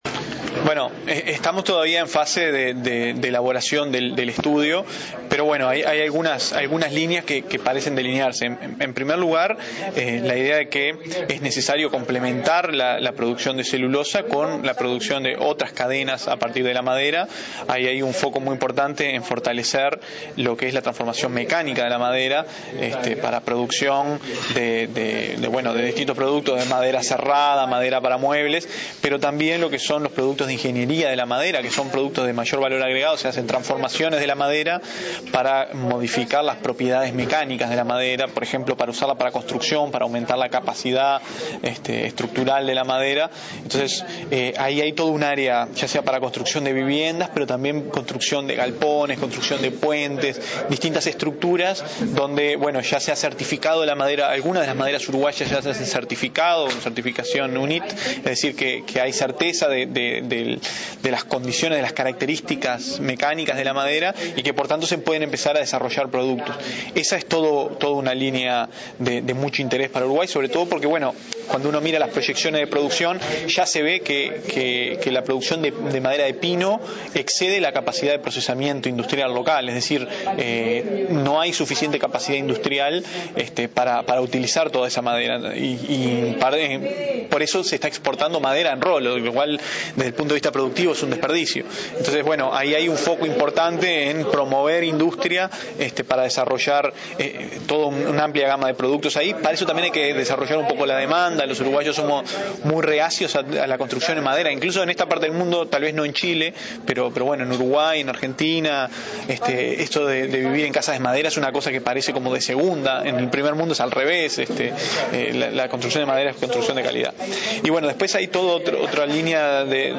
El director de Planificación de la OPP, Fernando Isabella, sostuvo que es necesario complementar la producción de celulosa con otras cadenas a partir de la madera, como construcción de casas, energía o biorefinería. En el marco de un evento sobre bioeconomía forestal hacia 2050, dijo que OPP analiza las posibilidades científico-tecnológicas para traducirlas a costos y acciones que posibiliten el desarrollo de manera rentable.